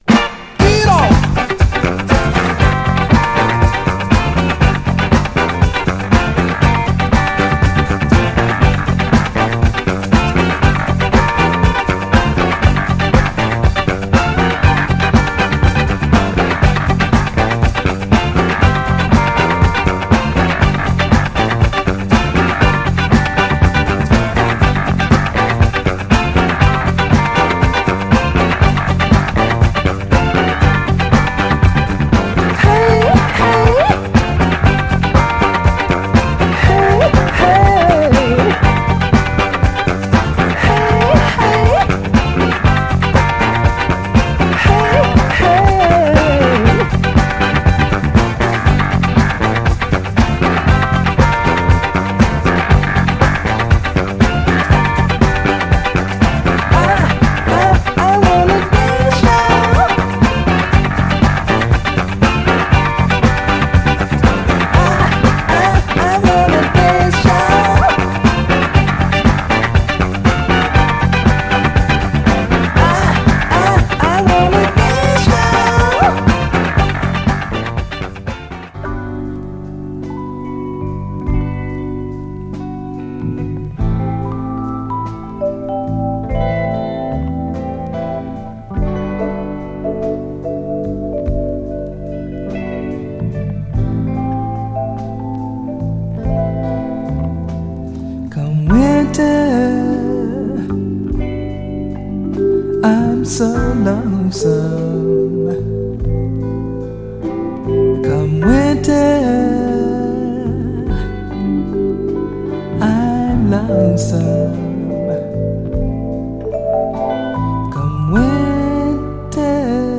> FUNK 45's
掻き鳴らすシグネチャー・ギターで突っ走る疾走ディスコ・ファンク
盤はいくつか薄いスレ箇所ありますが、音への影響は殆ど無くプレイ良好です。
※試聴音源は実際にお送りする商品から録音したものです※